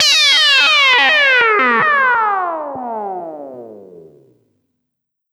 Ring Mod Fall 1.wav